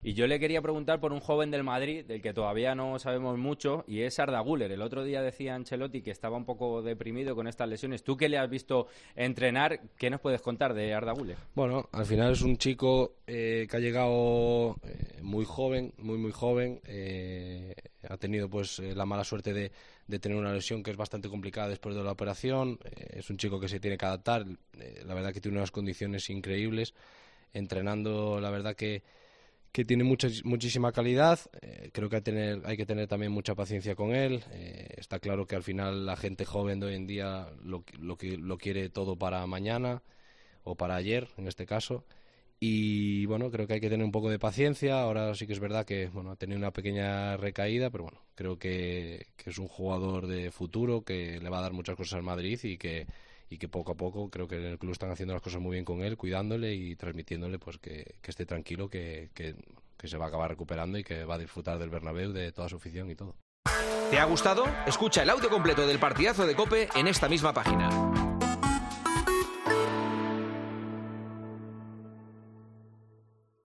AUDIO: La promesa turca no ha podido debutar todavía en encuentro oficial con la camiseta blanca y Juanma Castaño le preguntó al delantero en El Partidazo de...